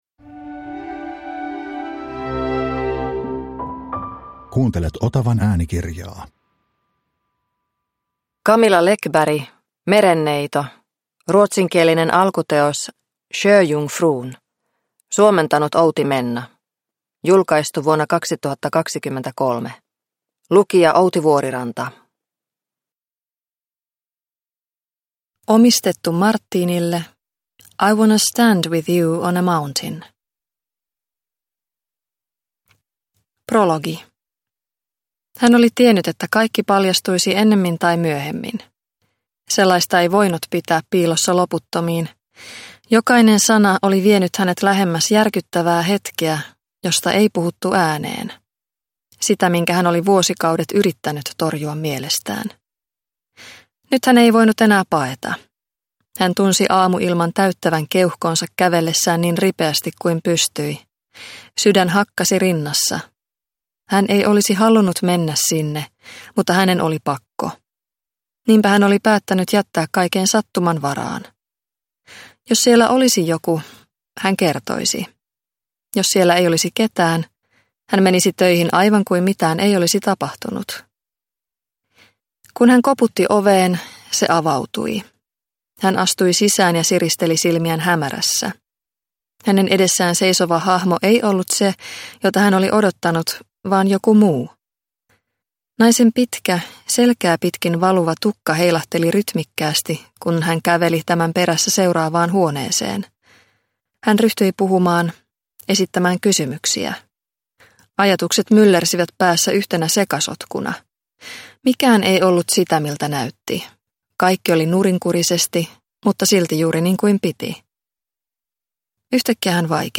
Merenneito – Ljudbok – Laddas ner